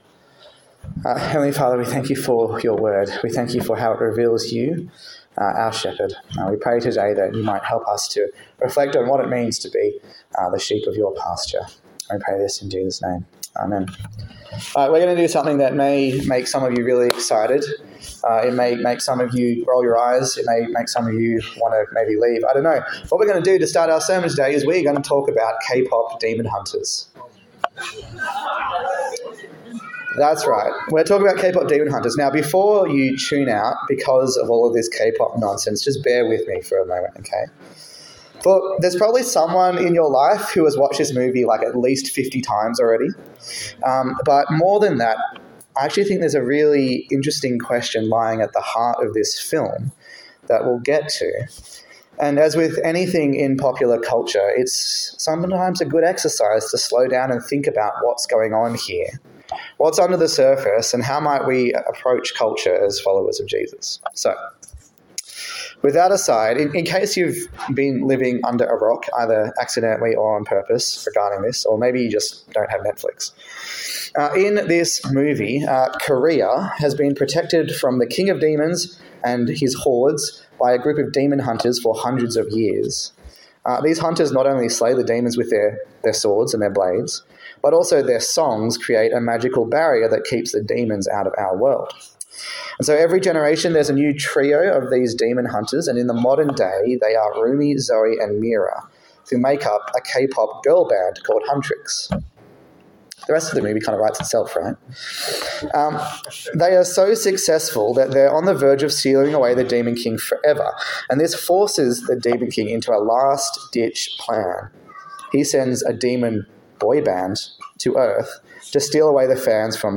Ezekiel Passage: Ezekiel 33 and 34 Service Type: Morning Service